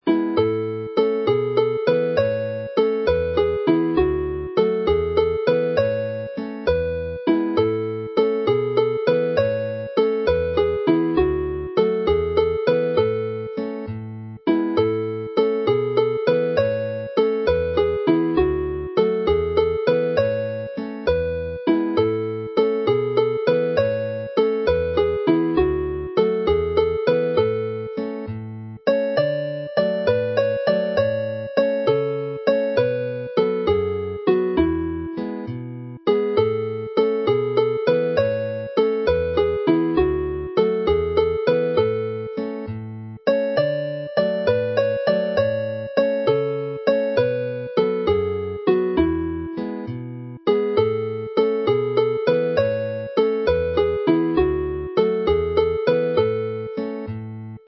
Play the melody slowly